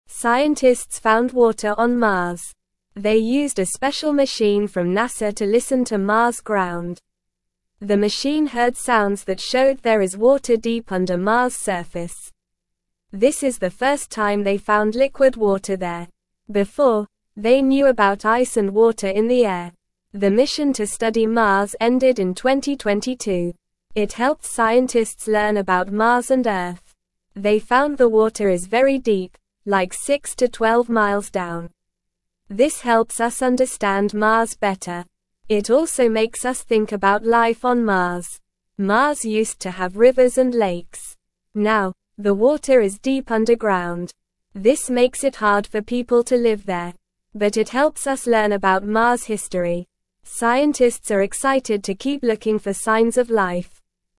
Normal
English-Newsroom-Beginner-NORMAL-Reading-Water-Found-on-Mars-Deep-Underground-Excites-Scientists.mp3